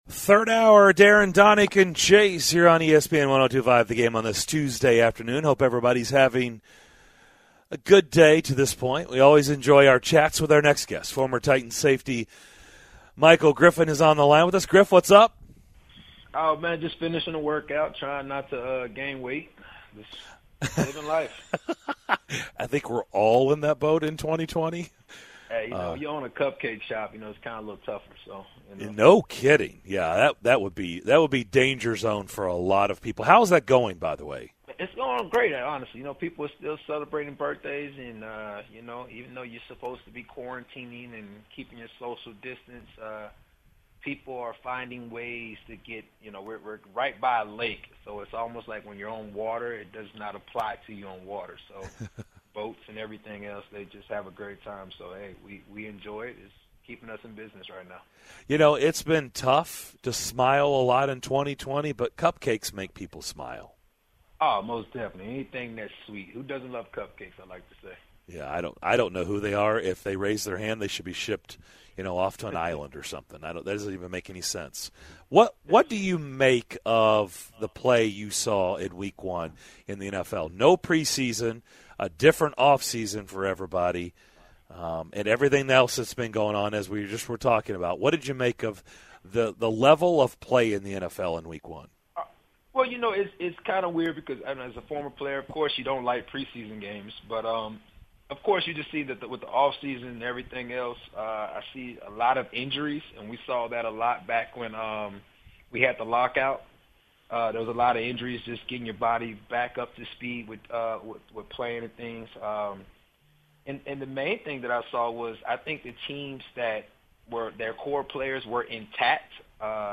In the third hour of Tuesday's show: the guys talk with former Titans safety Michael Griffin about the Titans big win over the Broncos, the guys listen in on Mike Vrabel's press conference and more!